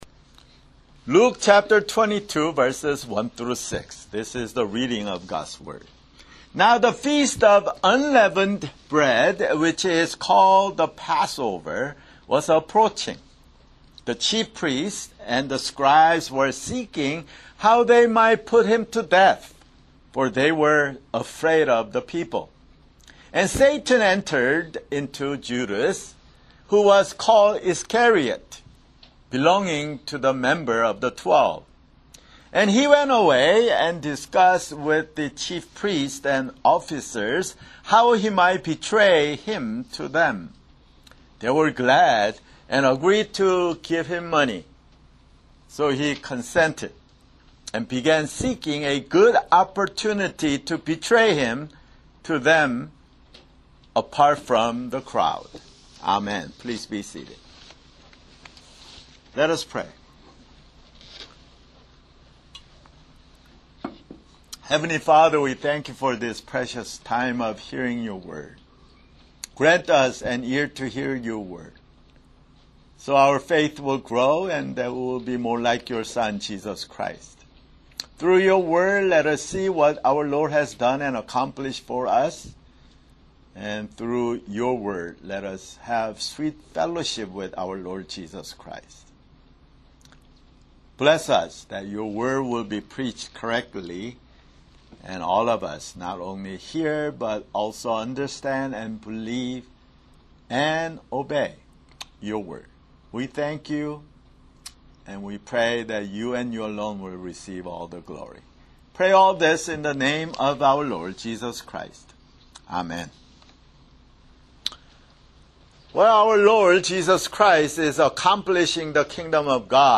[Sermon] Luke (147)